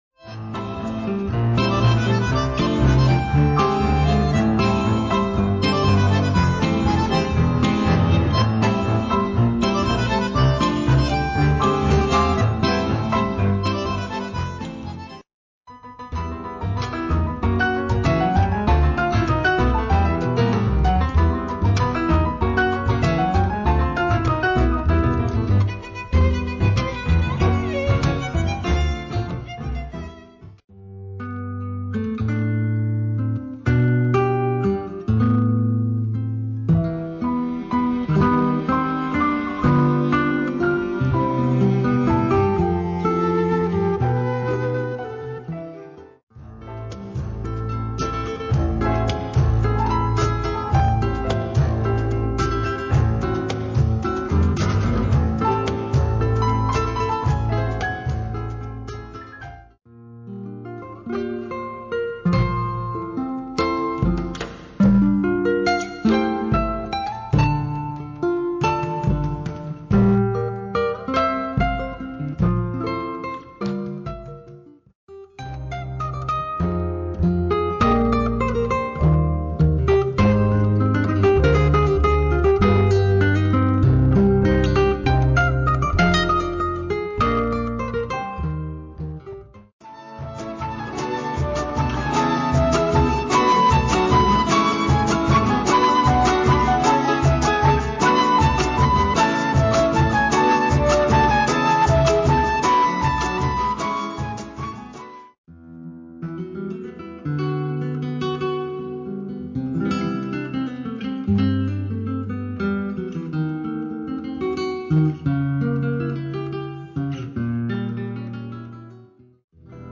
Latin Dance Collection